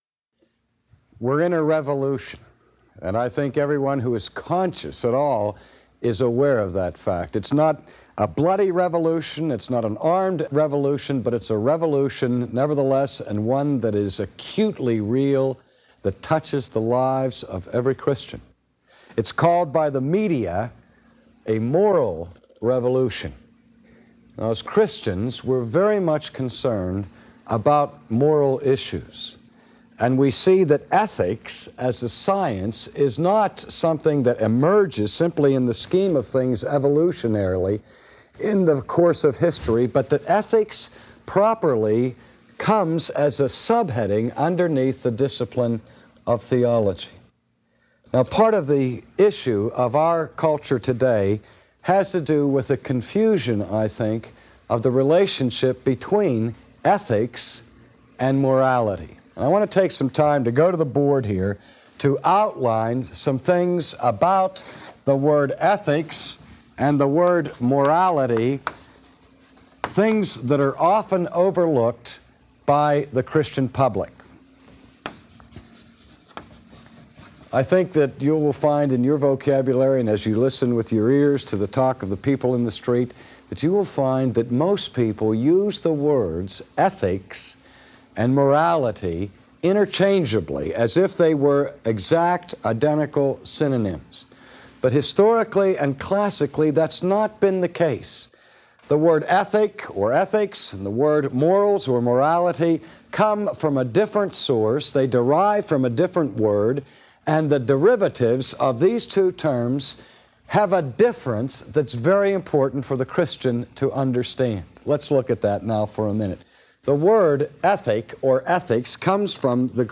This was the introductory lecture.